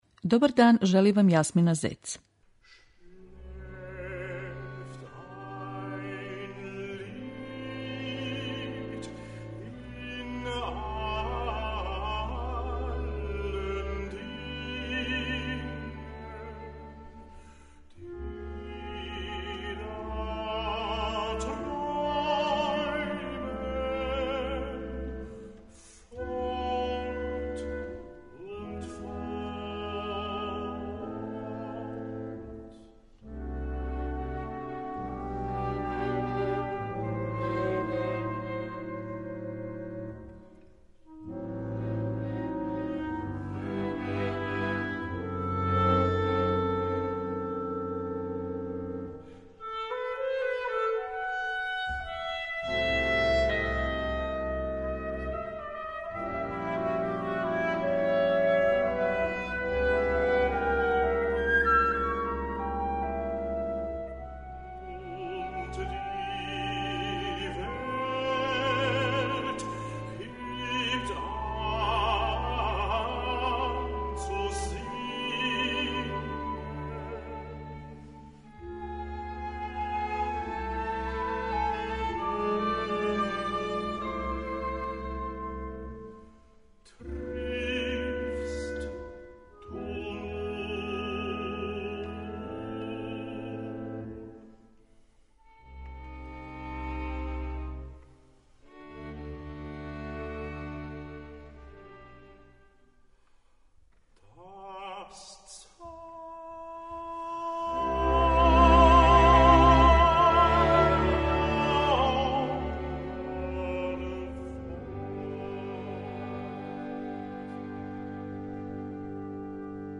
Емисију посвећујемо Кристофу Прегардиену, славном немачком тенору који ужива репутацију специјалисте за немачи лид.
Представићемо га снимнцима оствареним у концертним дворанама и композицијама Роберта Шумана, Јозефа Хајдна и Густава Малера.